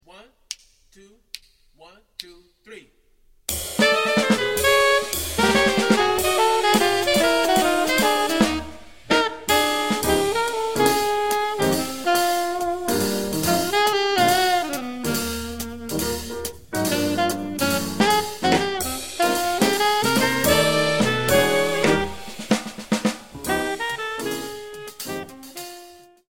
quintet, with two horns